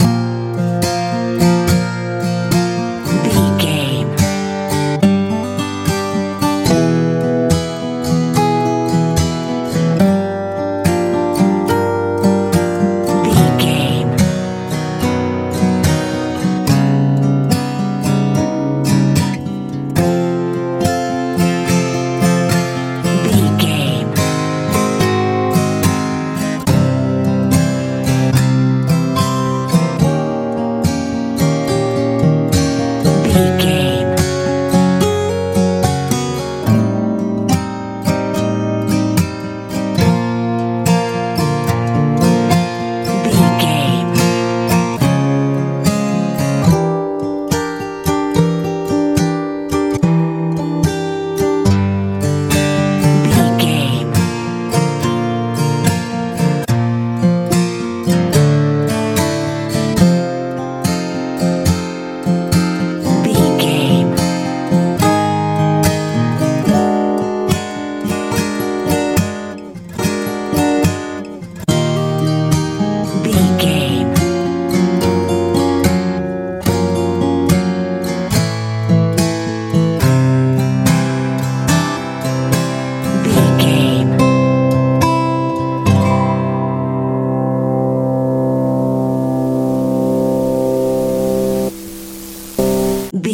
campfire feel
Ionian/Major
B♭
light
acoustic guitar
southern